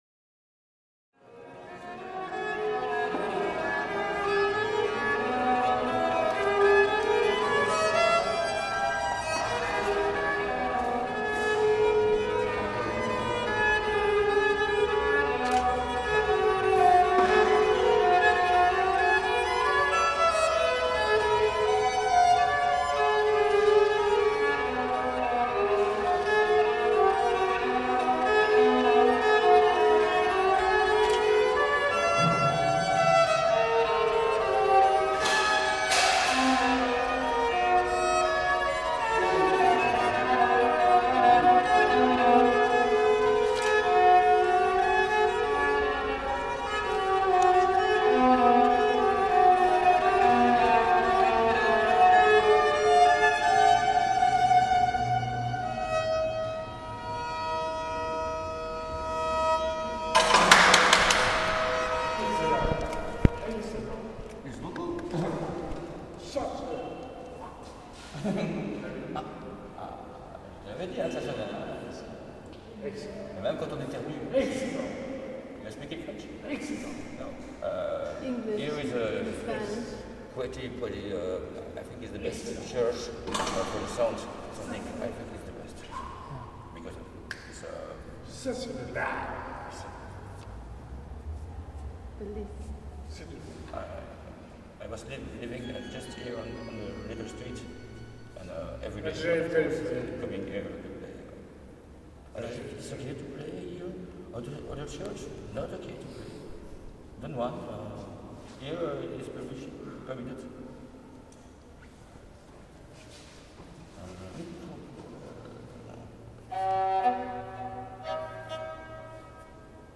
A solo violinist in Baroque church of Notre Dame de Bon Secours, Brussels
Going past today – World Listening Day – I noticed some music, and went in to where a man was playing, unamplified, in the centre of the church. People came and went to admire.